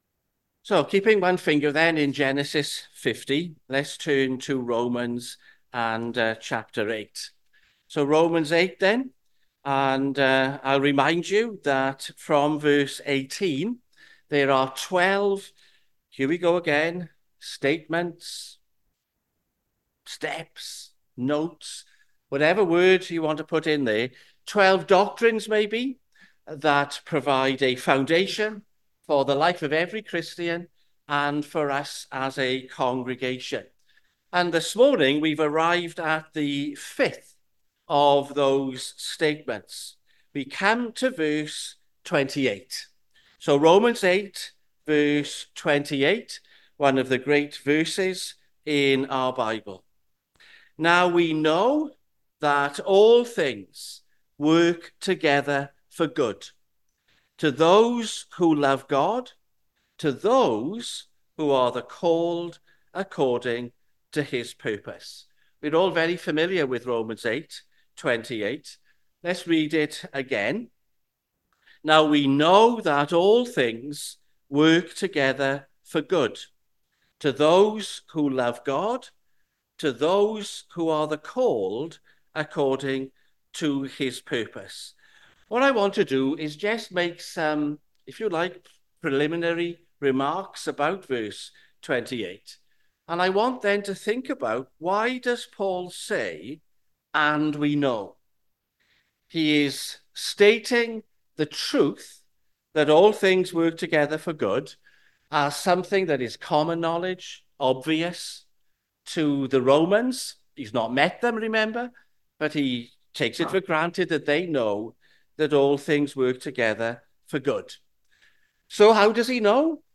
Sermons Tabernacle church - 13th October 2024 -All things work together for good Play Episode Pause Episode Mute/Unmute Episode Rewind 10 Seconds 1x Fast Forward 30 seconds 00:00 / 29:06 Subscribe Share RSS Feed Share Link Embed